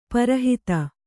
♪ para hita